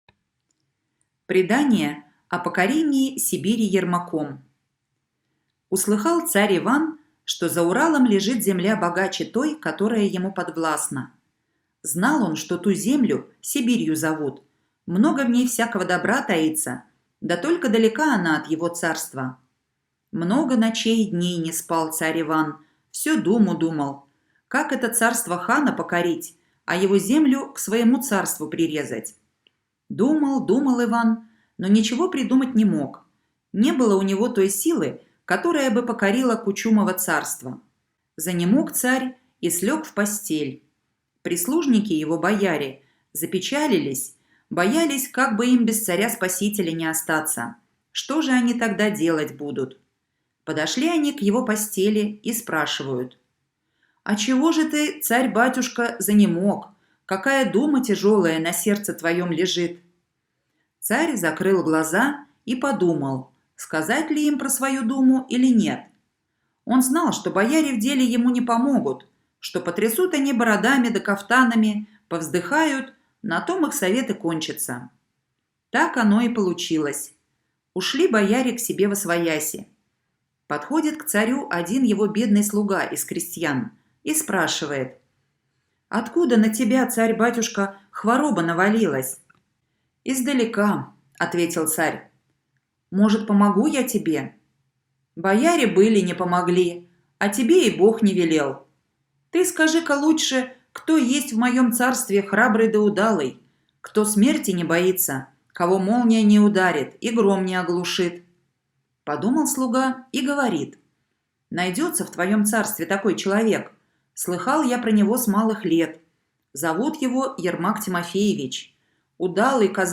Легенда о покорении Сибири Ермаком - аудио былина. Легенда о том, как царь Иван послал лихого донского казака Ермака покорять Сибирь.